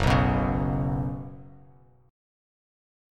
Gbsus4 chord